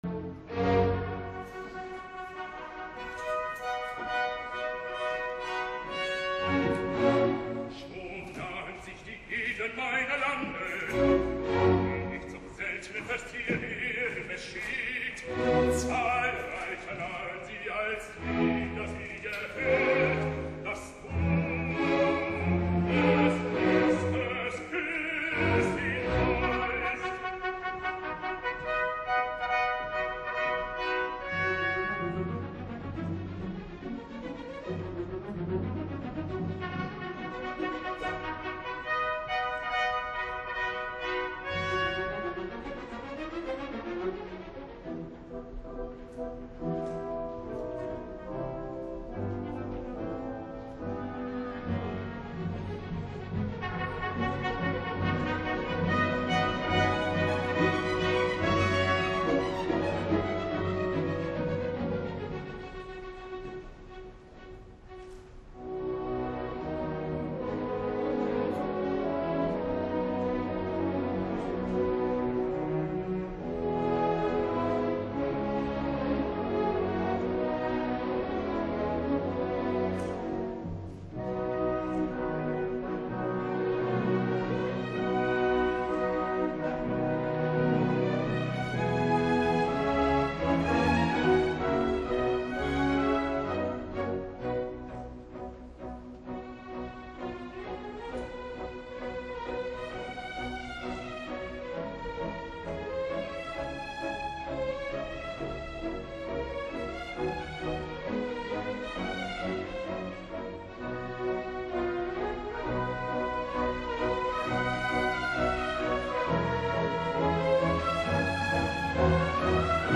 entrada del cor acte 2